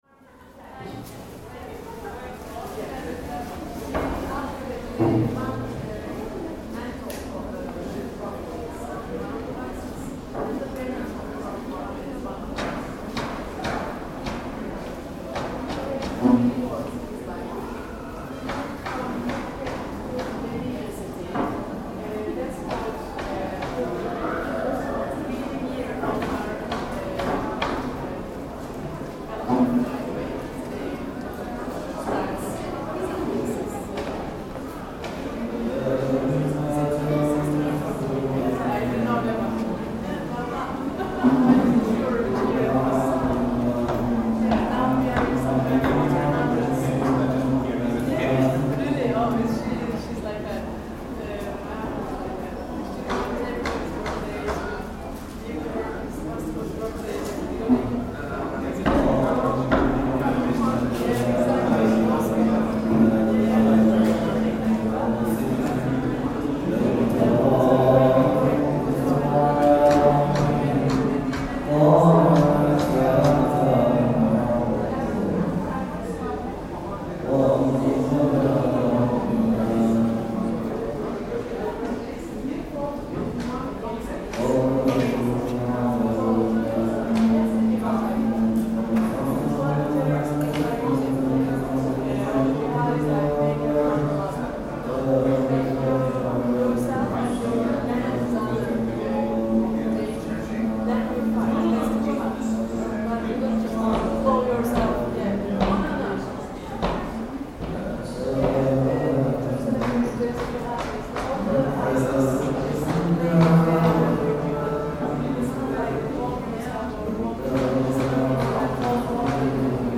The recording of Istanbul’s 15th-century Topkapı Palace captures the heartbeat of a space steeped in history and legacy.
The palace’s atmosphere unfolds here - a vivid collage of layered sounds. Visitors murmur in countless languages, their footsteps weaving through the courtyards. Tour guides narrate centuries-old stories, while distant echoes of scripture readings and archival texts enrich the air, connecting past to present. A moment of singing drifts gently, adding a spiritual tone that feels timeless and poignant, a reminder of the palace’s role as a place of governance and reflection. Listening to this, one can feel the profound duality of Topkapı - its' serenity mingling with the bustling energy of the modern world.